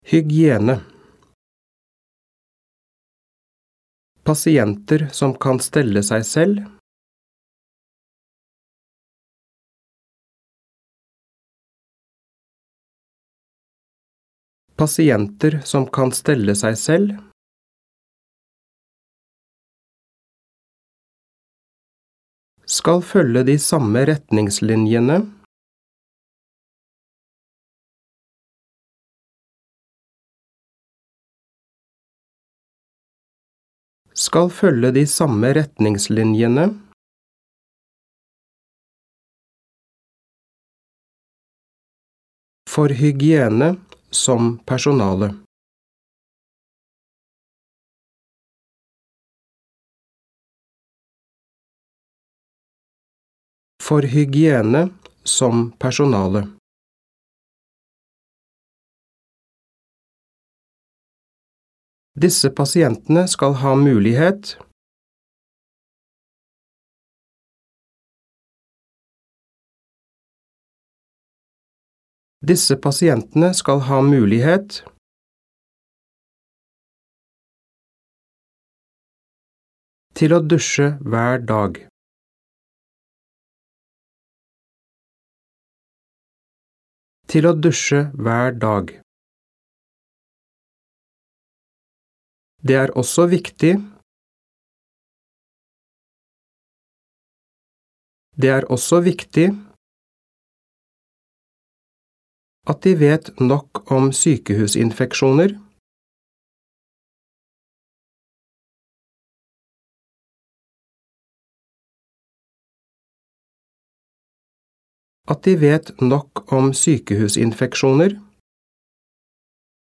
Diktat leksjon 5
• Andre gang leses hele setninger og deler av setninger.